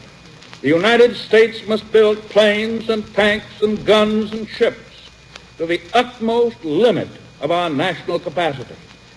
President Franklin Delano Roosevelt, in his December 9th, 1941 Fireside Chat broadcast to the American people over radio, stated that the